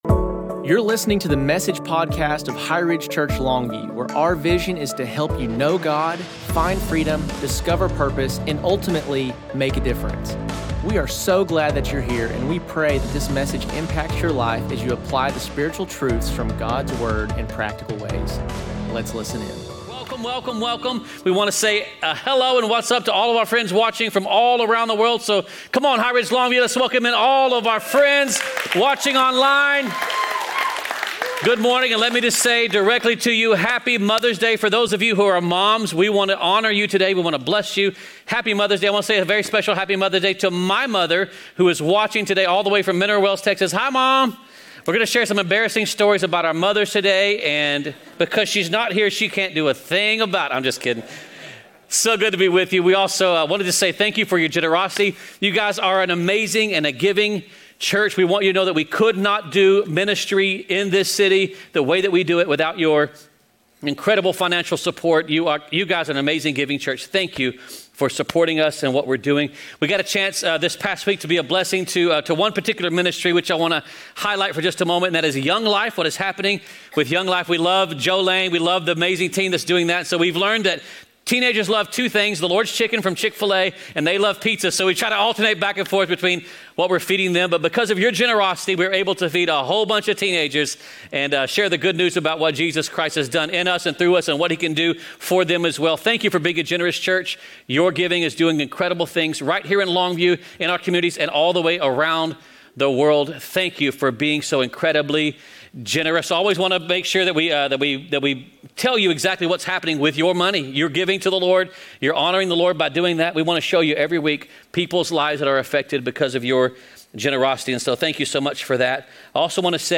2025 Message